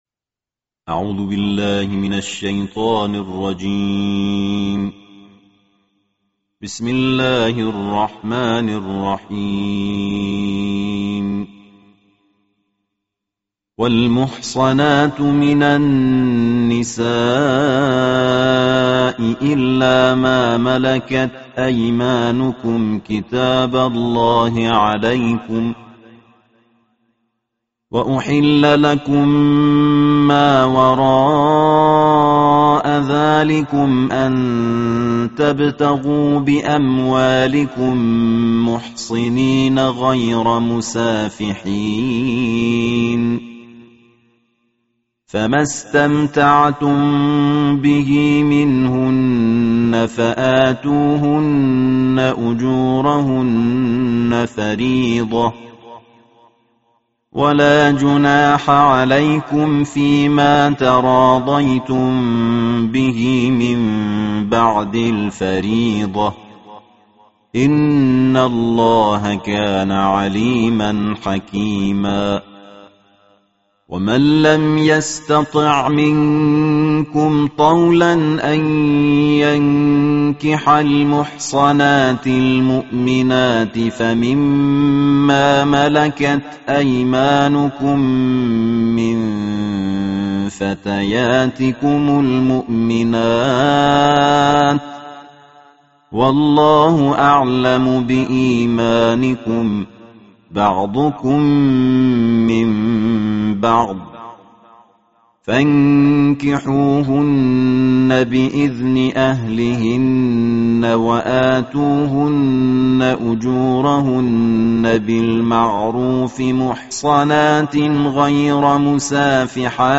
ترتیل جزء پنجم قرآن
این قاری بین‌المللی در این تلاوت جدید، سعی داشته تا در کنار اجرای مقامات اصیل عربی، از نغمات فارسی و کشورهای همسایه جهت تلمیح الحان بهره‌مند شود.